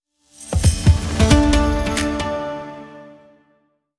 Brand Sonic logo